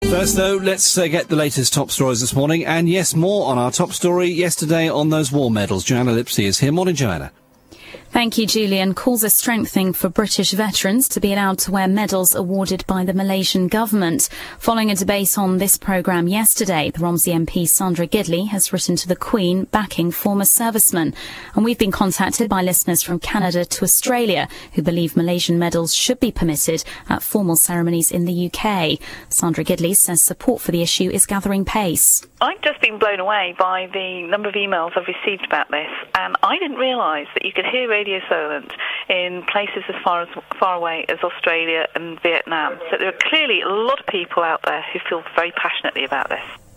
A local MP, Sandra Gidley, was brilliantly supportive of the PJM case on the 'wirless' this morning.